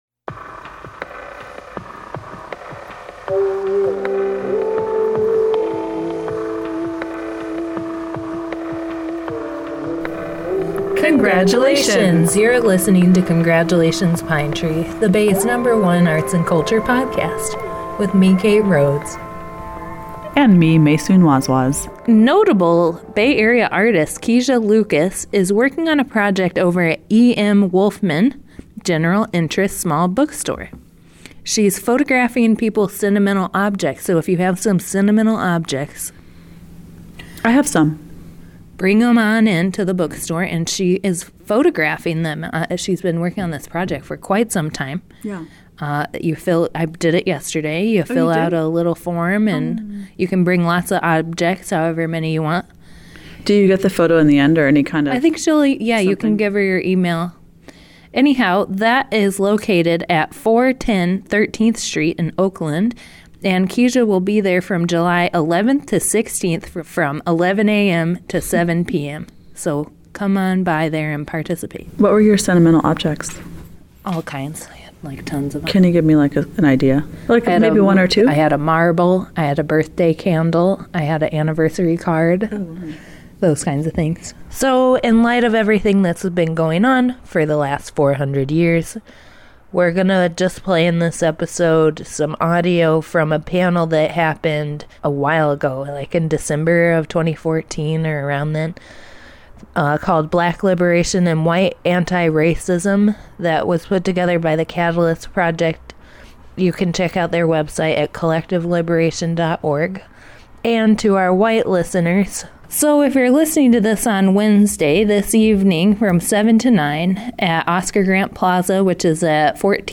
---------- This week instead of a new episode, we're playing the audio from a panel discussion titled, Black Liberation & White Anti-Racism in the Time of Ferguson, organized by the Catalyst Project at the Omni Commons in December, 2014.